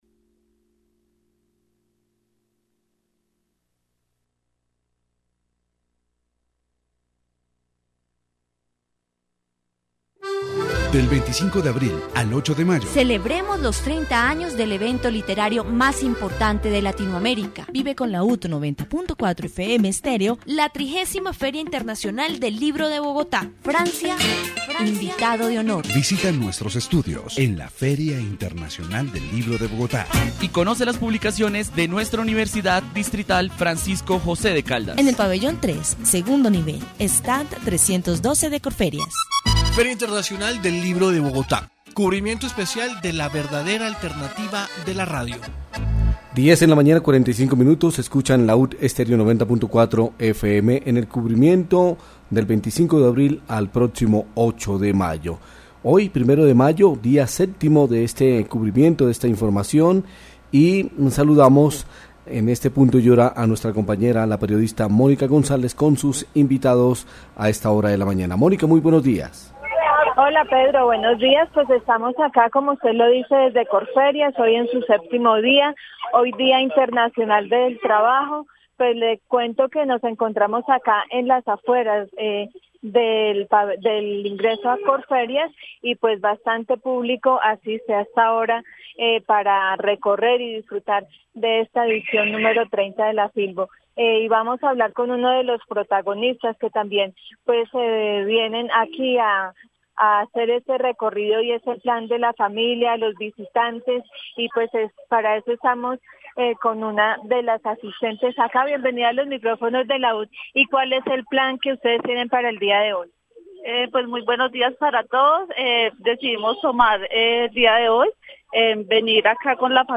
Feria del Libro 2017. Informe radial
Programas de radio